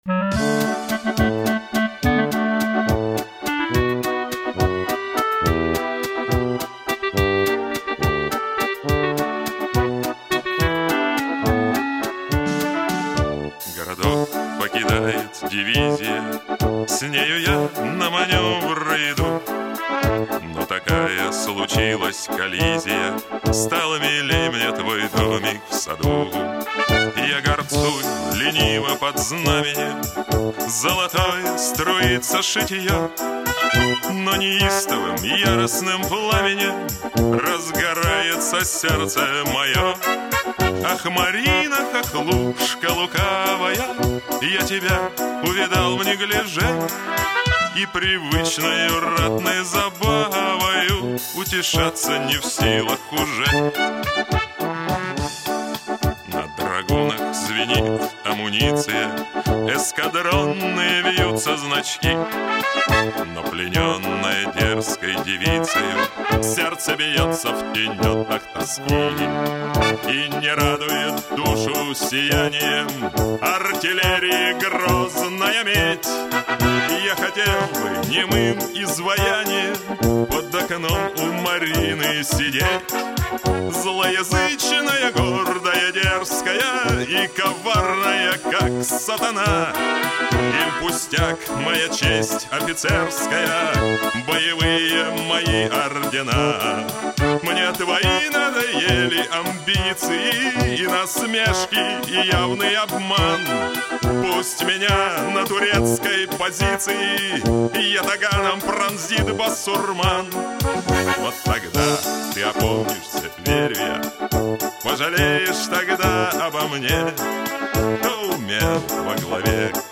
Бардрок (4123)